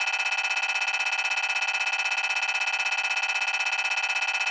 星际争霸音效-glue-scorefill.wav